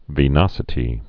(vē-nŏsĭ-tē)